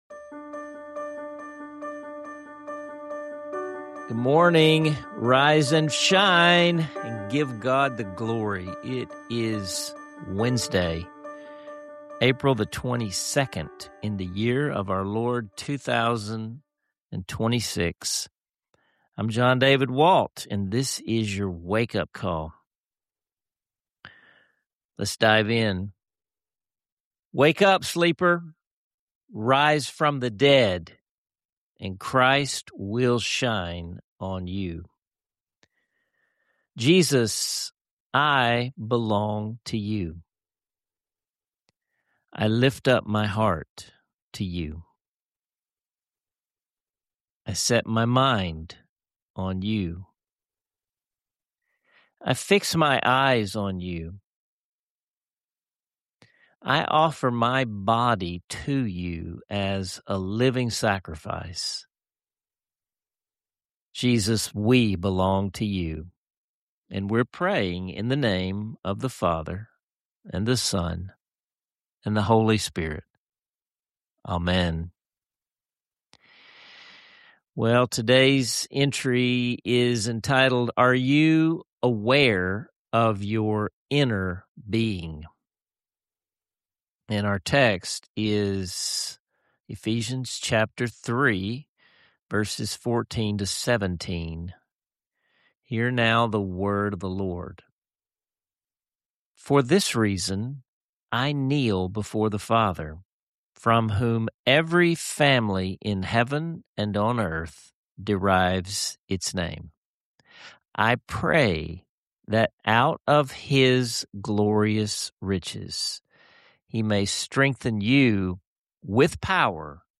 Reflect on meaningful prompts about your inner life, and let the episode’s soulful musical interlude draw you into your own secret place with God.